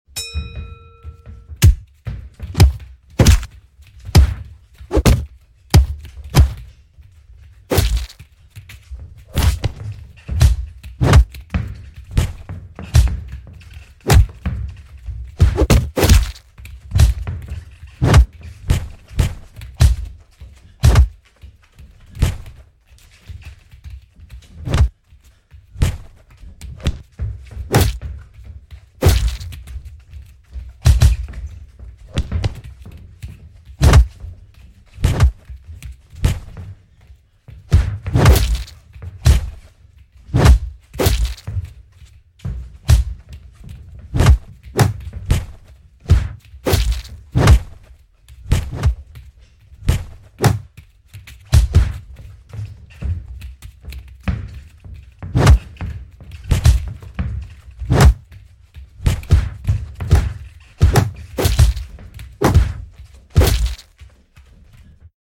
Boxing Fight Sound Effect (NO Sound Effects Free Download